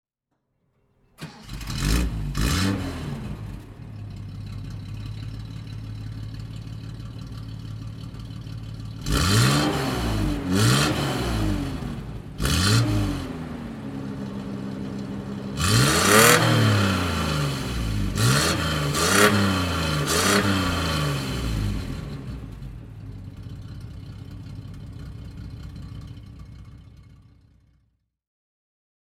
Datsun 260 Z 2+2 (1977) - Starten und Leerlauf